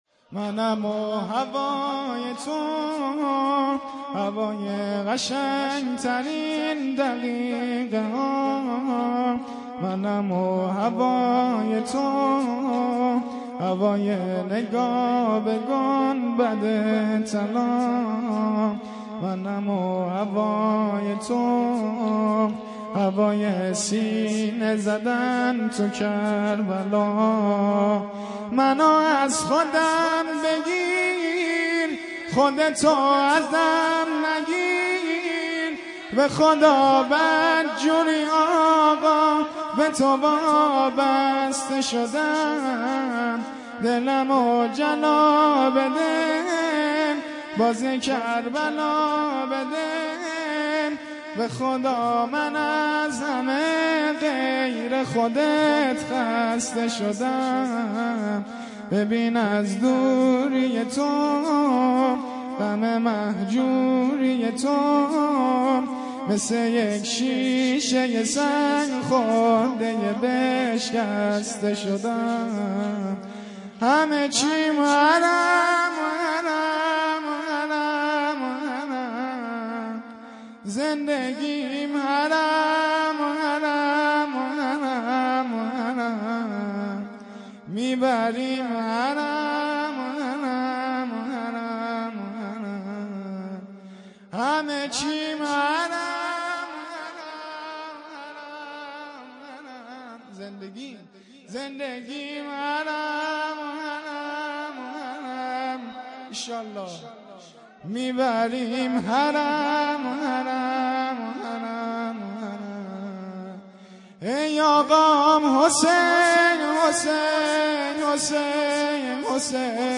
شور
شب سوم محرم ۱۴۰۱